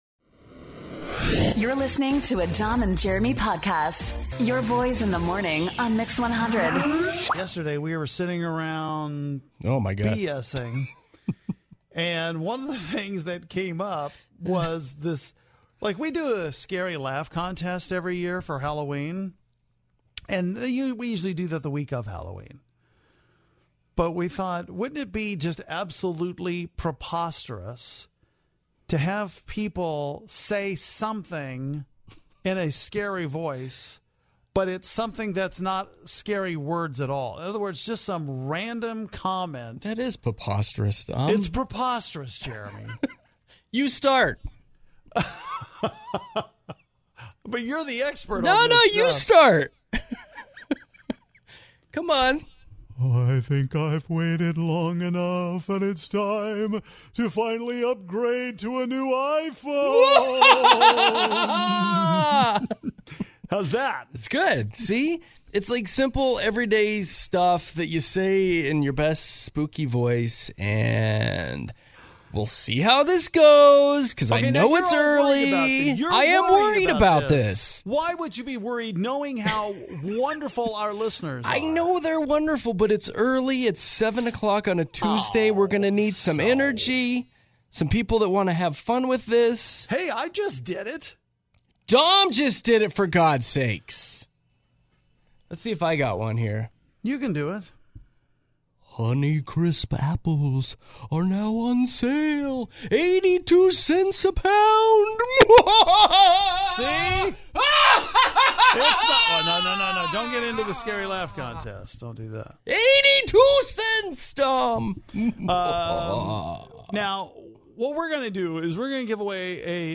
In the spirit of Halloween, we find out who has the scariest voice!